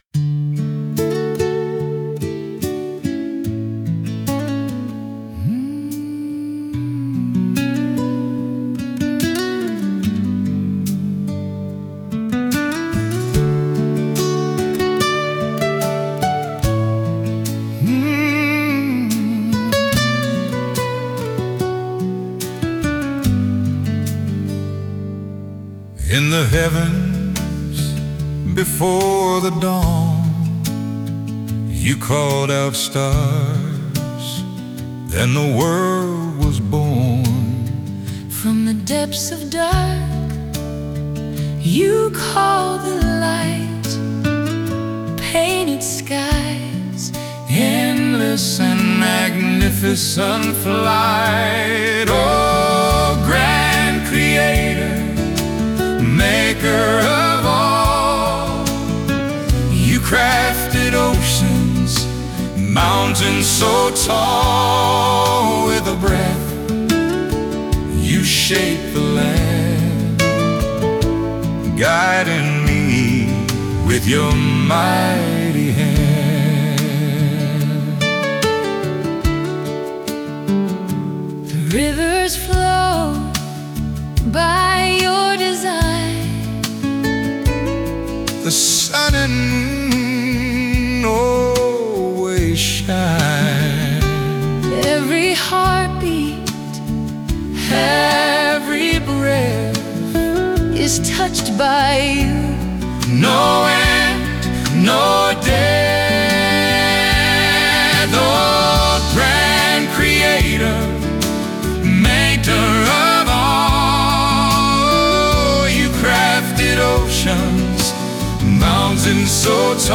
Encouraging and emotional Songs